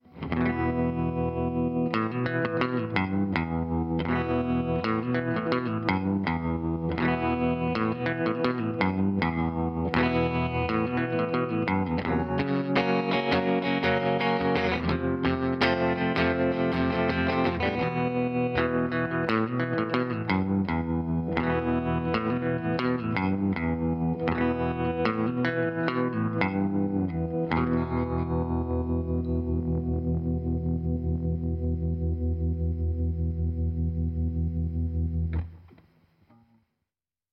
Electric Guitar (clean) - LCT 440 PURE